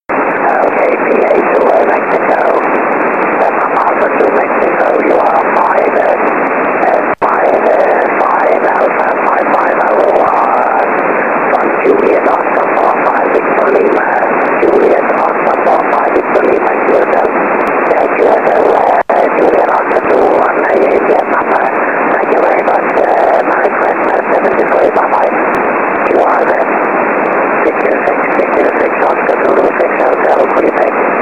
SSB Aurora on 6 m:
😉 Both were about equal signal strength.